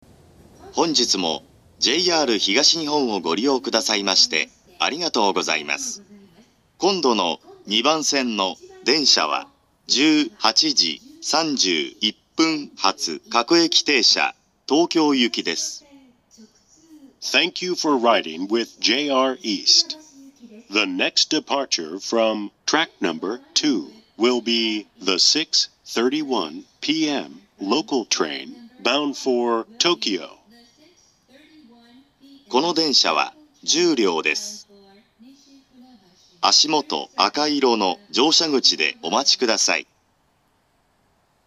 ２０１６年９月２５日には、自動放送がＡＴＯＳ型放送に更新されています。
２番線到着予告放送